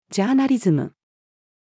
journalism-female.mp3